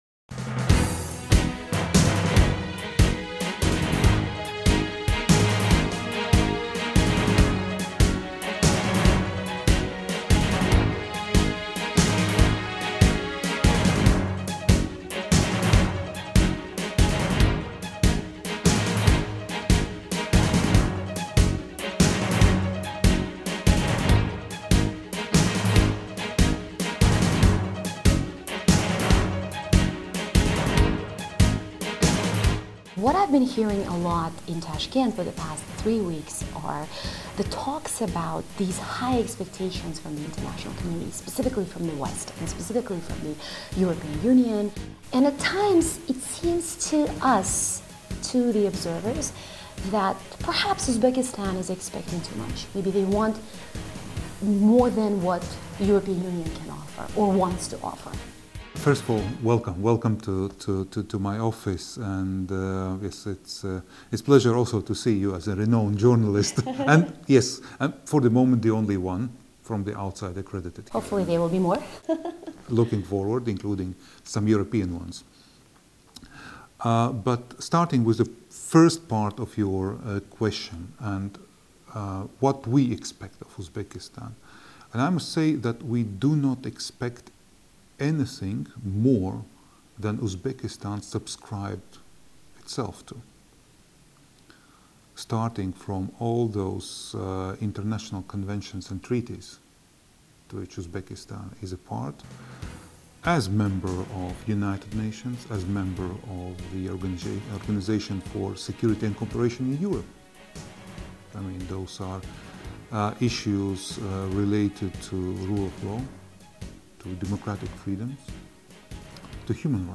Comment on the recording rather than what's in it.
Now is the European Union opening a new and more dynamic chapter in its relations with the region, and especially with a changing Uzbekistan? VOA special from Tashkent.